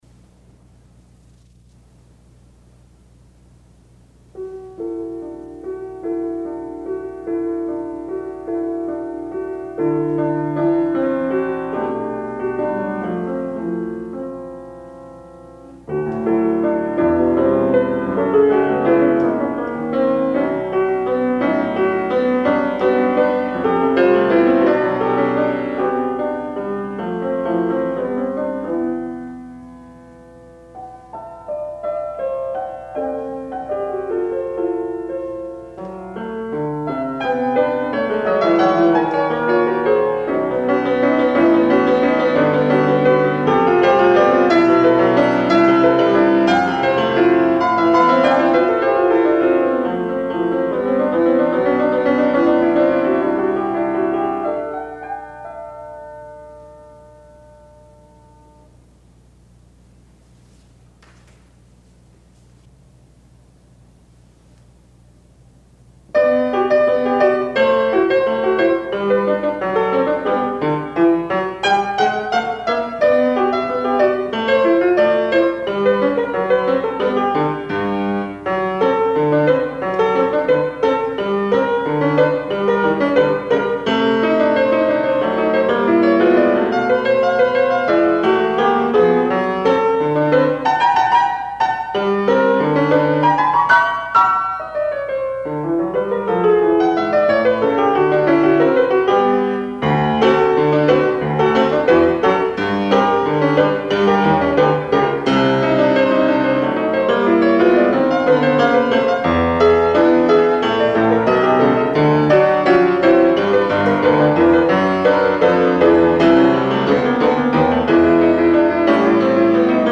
for Piano (1977)
piano.